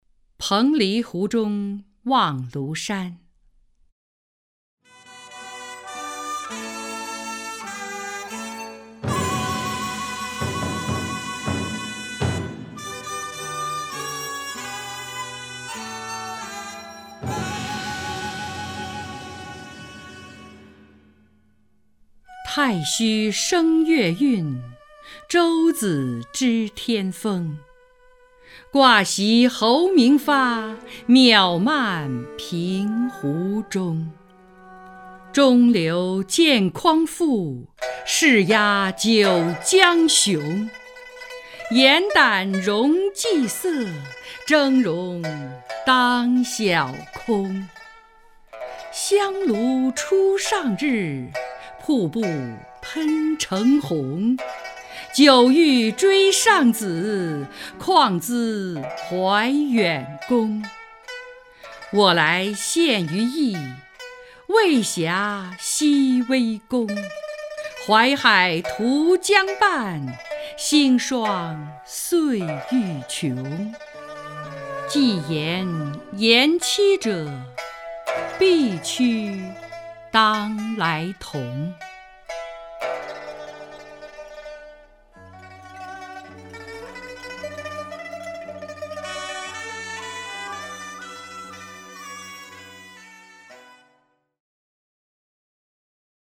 张筠英朗诵：《彭蠡湖中望庐山》(（唐）孟浩然)
名家朗诵欣赏 张筠英 目录